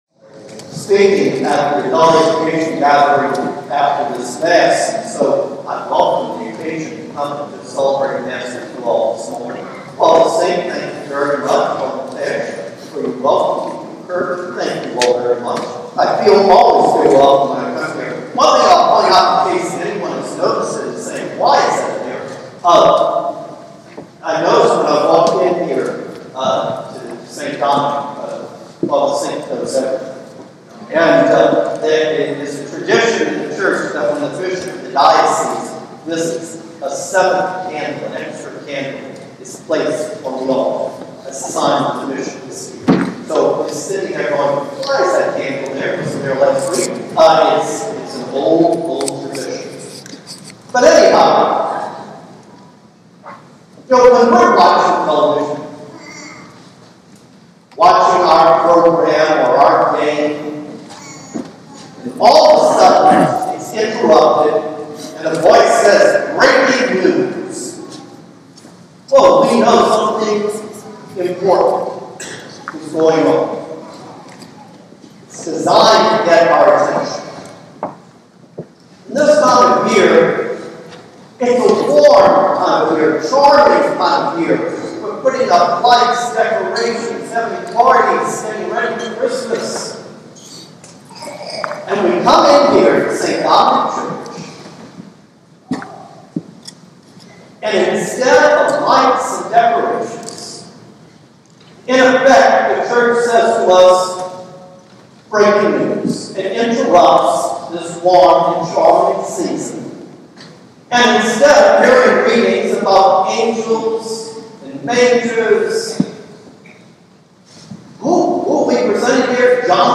Archbishop Rodi Homily for the 3 Sunday of Advent
We had special Guest at 9 am Mass, and he gave us very interesting and insightful homily.
rodi-homily-3-advent.mp3